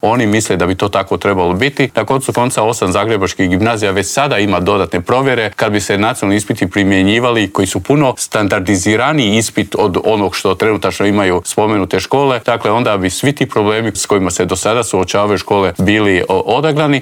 ZAGREB - U Intervjuu tjedna Media servisa razgovarali smo s ravnateljem Nacionalnog centra za vanjsko vrednovanje obrazovanja, Vinkom Filipovićem.